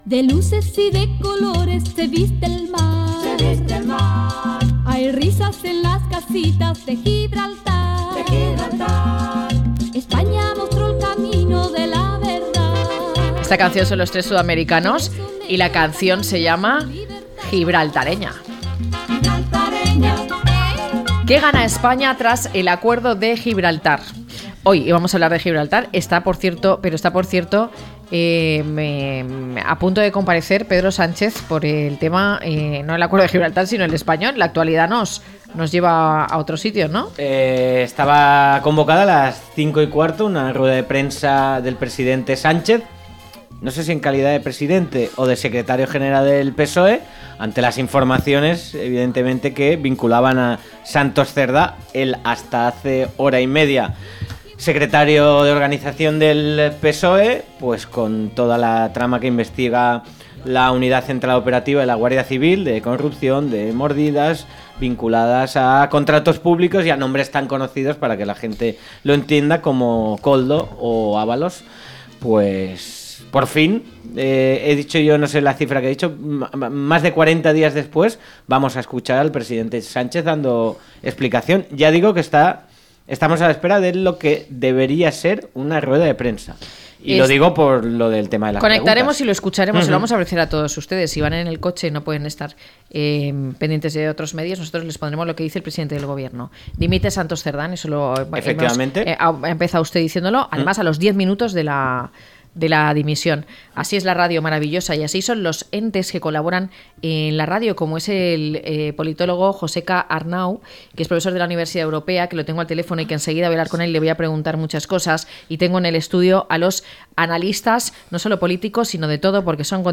0612-LTCM-DEBATE.mp3